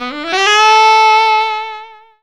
JAZZ SCALE 2.wav